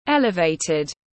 Vị trí cao hơn tiếng anh gọi là elevated, phiên âm tiếng anh đọc là /ˈel.ɪ.veɪ.tɪd/ .
Elevated /ˈel.ɪ.veɪ.tɪd/